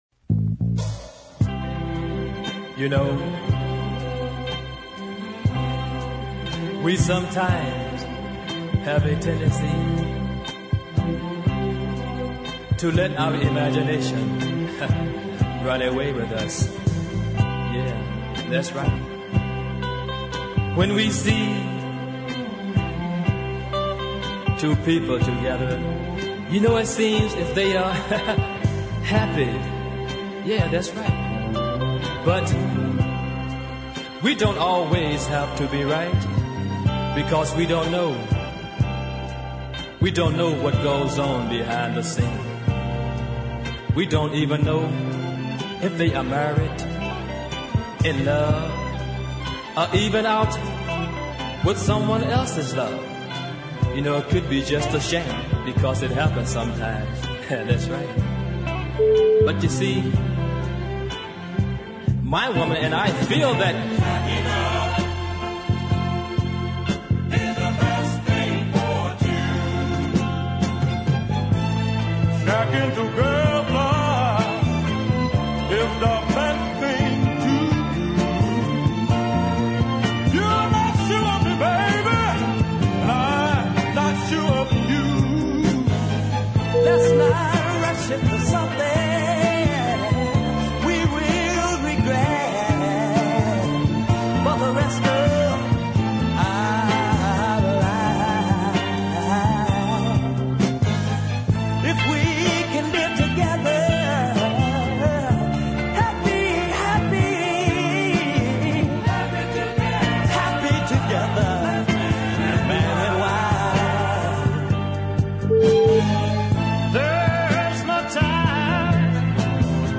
really strong soul group 45